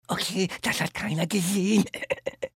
The amusing winter games feature the original German voices of Sid, Manny, Diego, and Ellie!